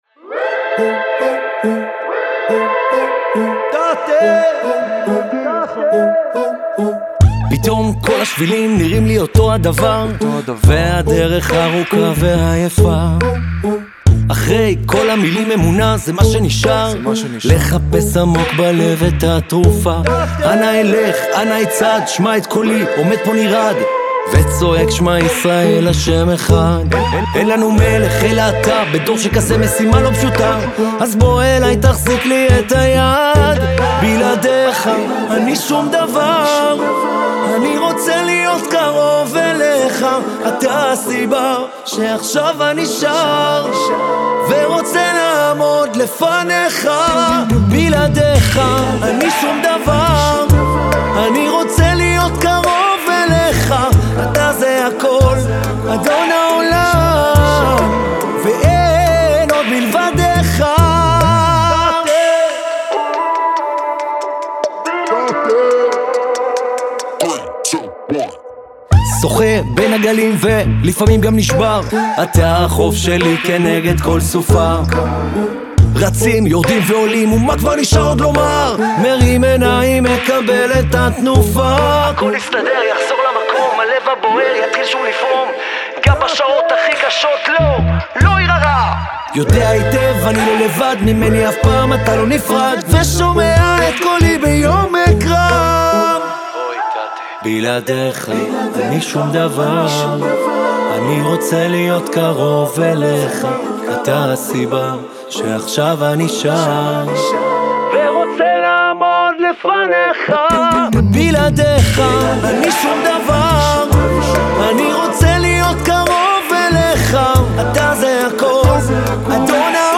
ווקאלי מילים